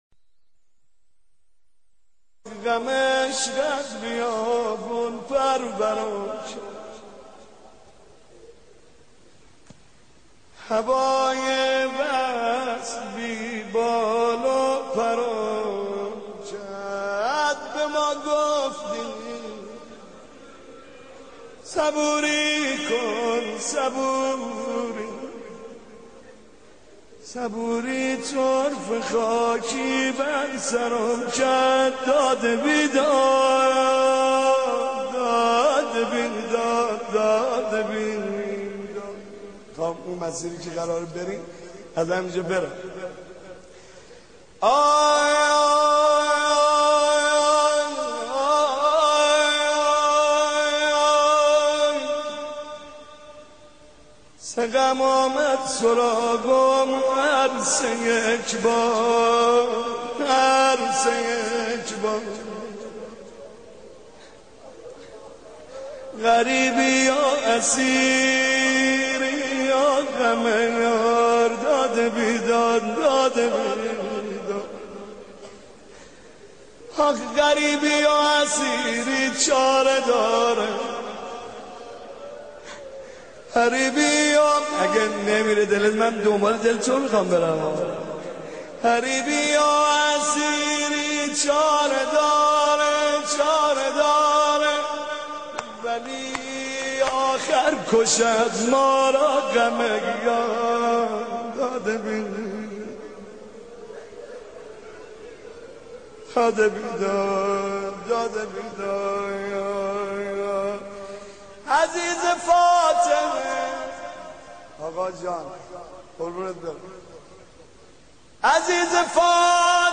مناجات با امام زمان ع با صدای حاج محمد رضا طاهری -( غم عشقت بیابون پرورم کرد )